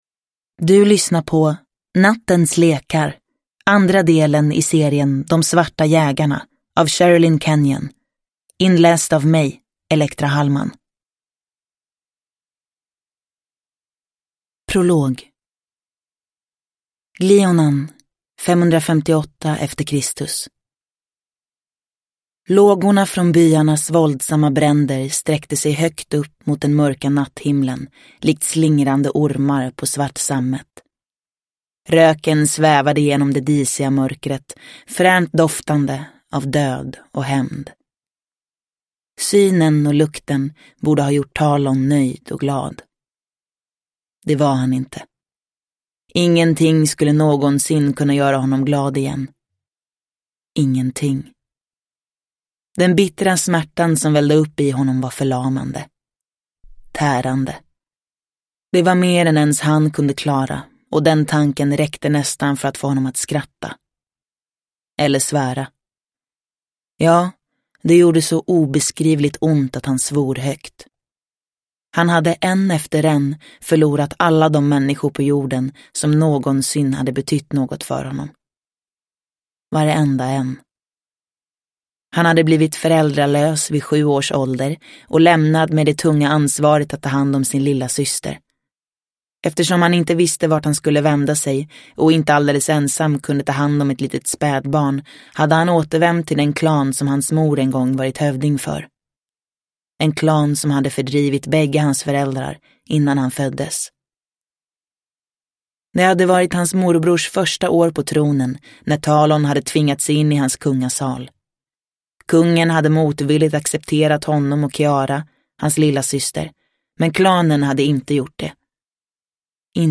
Nattens lekar – Ljudbok – Laddas ner